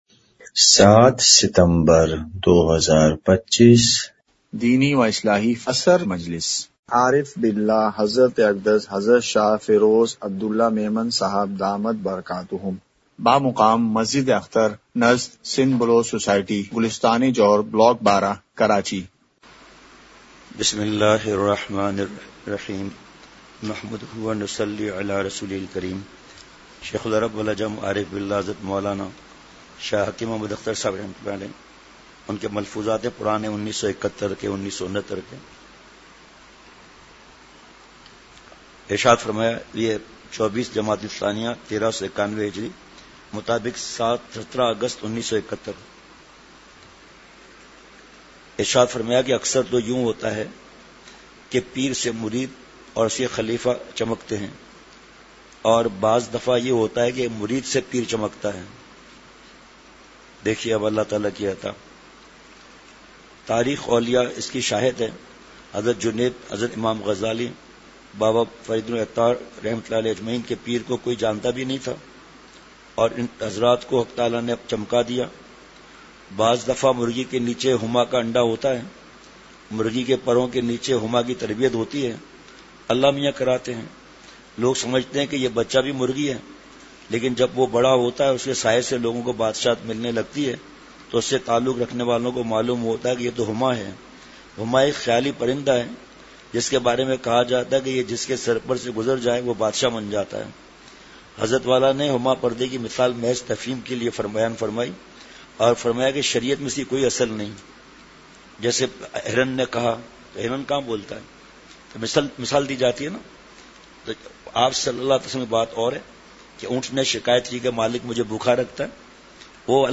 *مقام:مسجد اختر نزد سندھ بلوچ سوسائٹی گلستانِ جوہر کراچی*